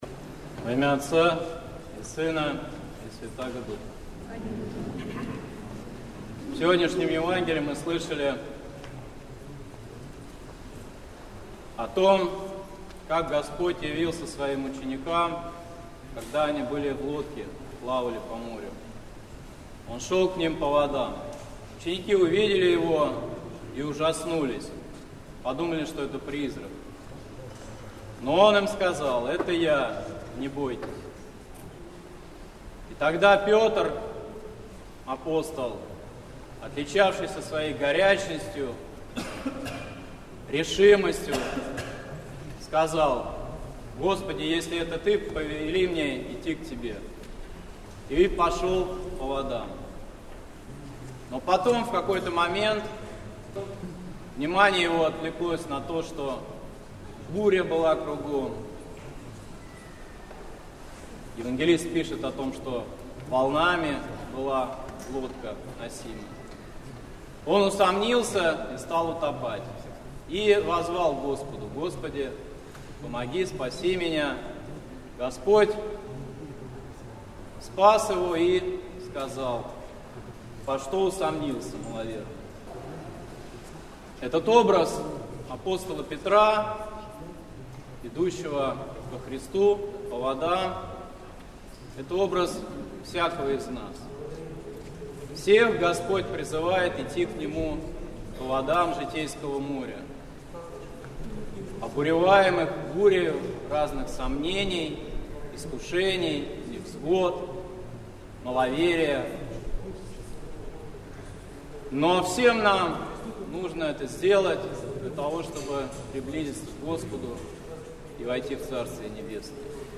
Слово в Неделю 9-ю по Пятидесятнице. Память Почаевской иконы Божией Матери
как от моря Галилейского чистым ветром повеяло от голоса вашего.